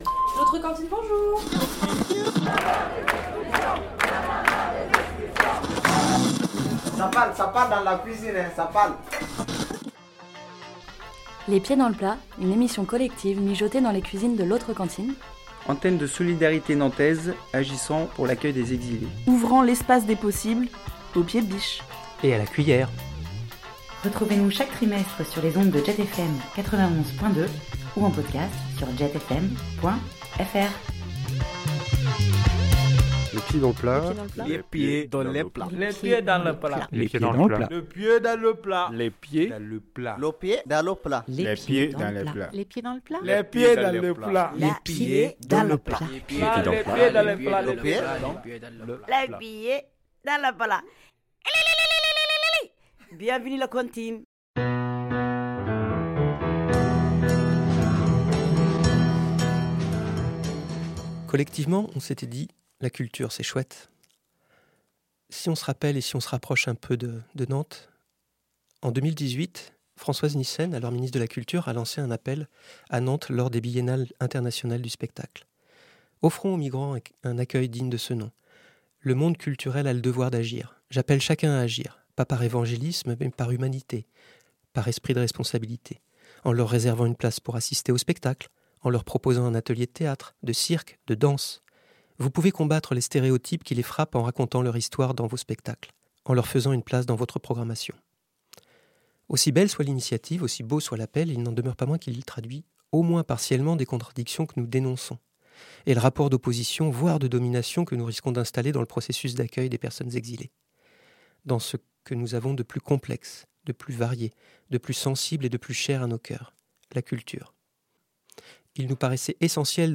Une émission collective, mijotée dans les cuisines de l'autre cantine